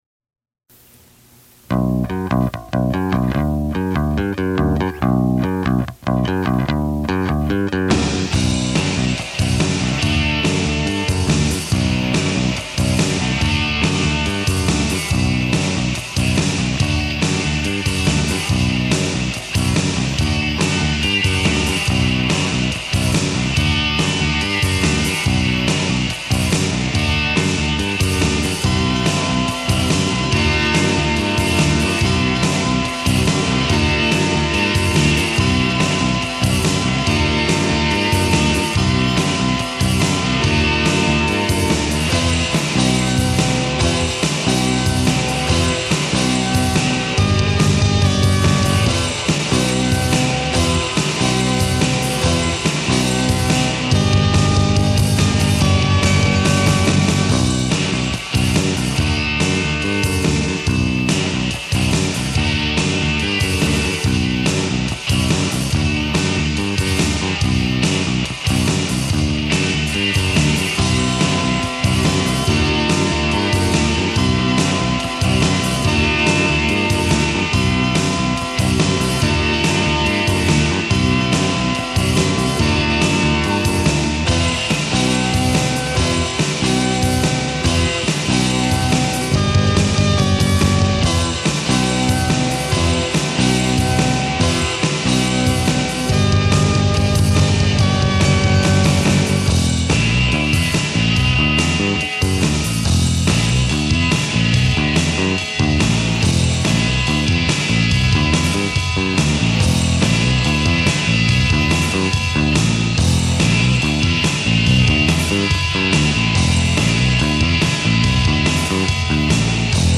as an instrumental in 1988 or thereabouts.